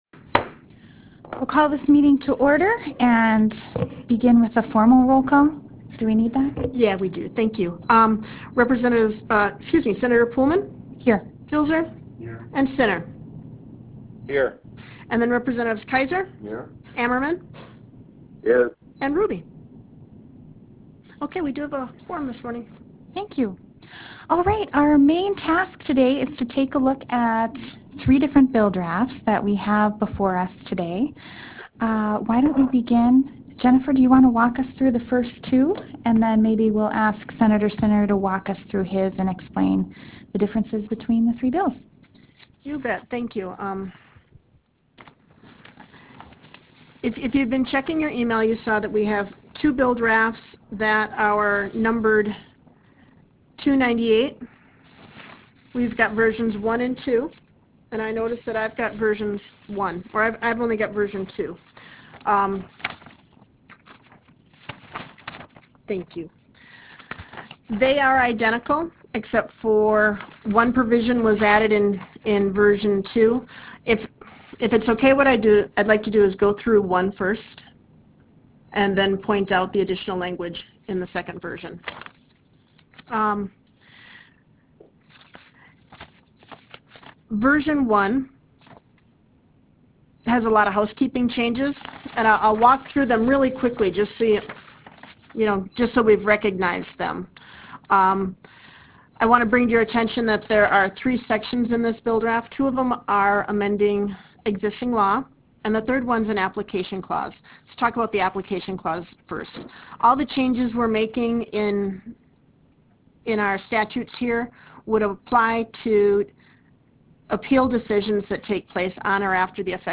This meeting will be conducted by teleconference call connection.
Harvest Room State Capitol Bismarck, ND United States